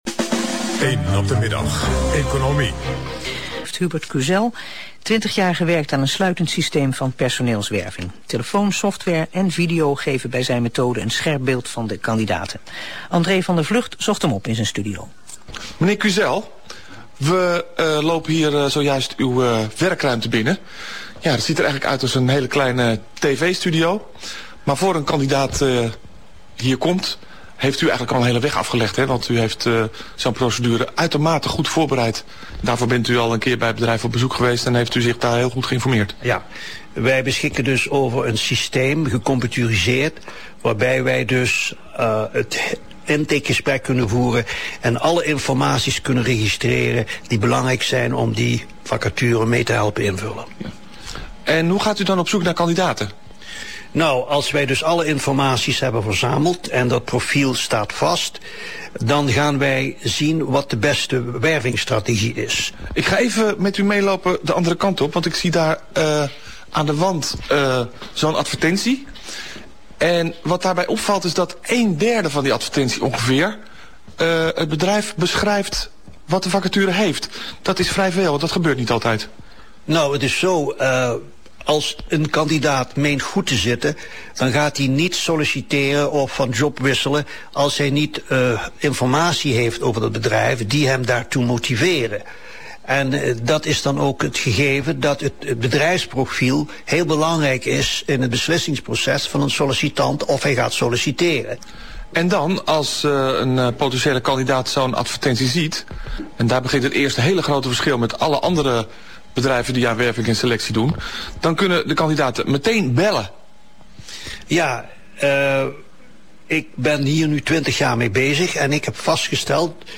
Een reportage van de Nederlandse AVRO